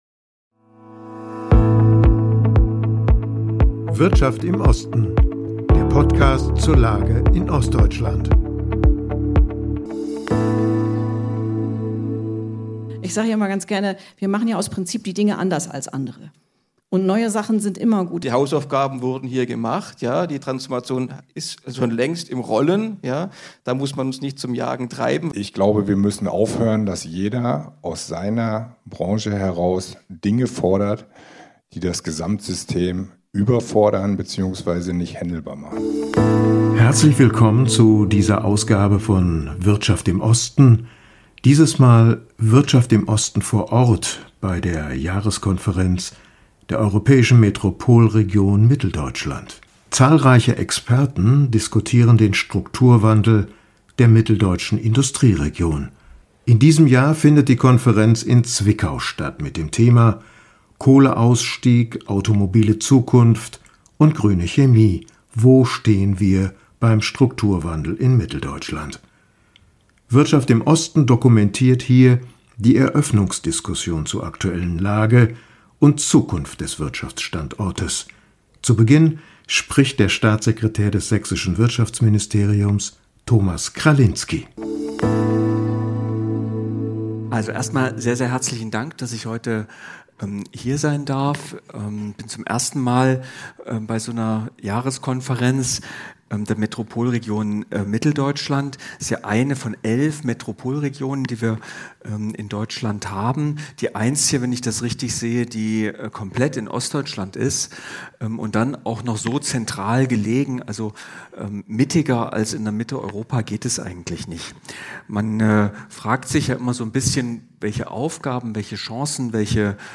WiO vor Ort: Jahreskonferenz 2025 der Europäischen Metropolregion Mitteldeutschland